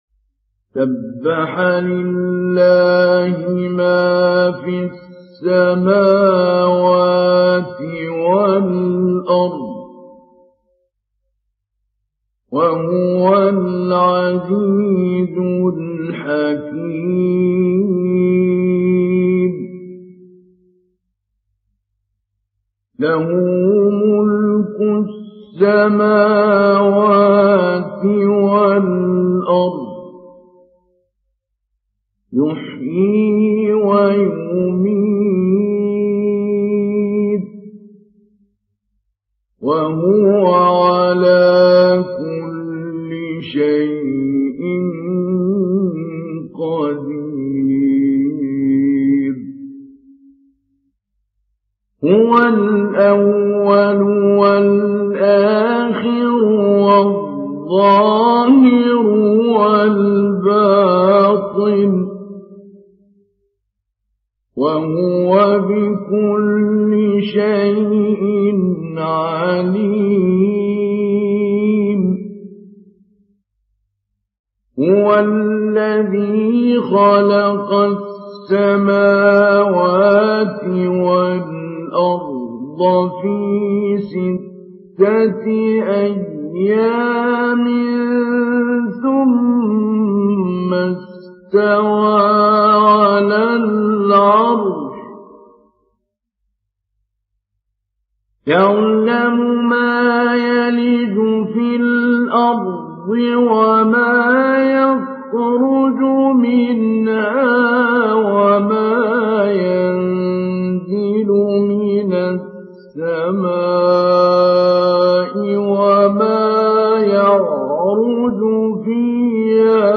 Télécharger Sourate Al Hadid Mahmoud Ali Albanna Mujawwad